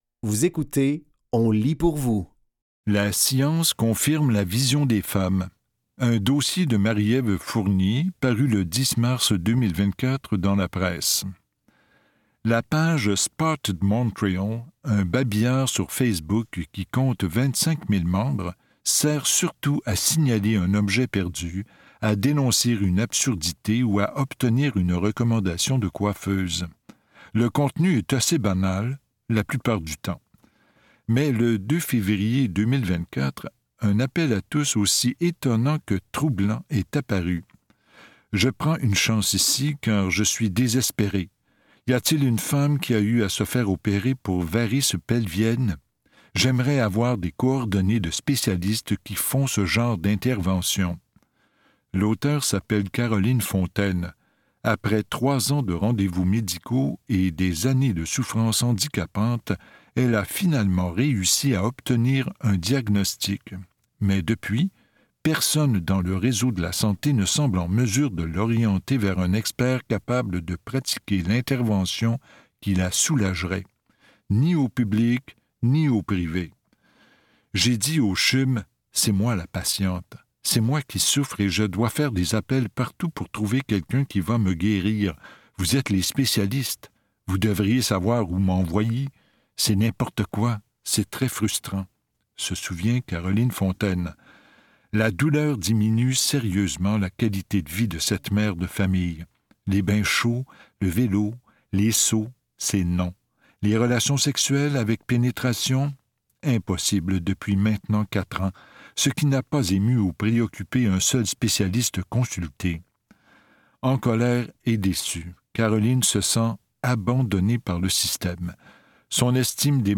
Dans cet épisode de On lit pour vous, nous vous offrons une sélection de textes tirés des médias suivants : Urbania et La Presse.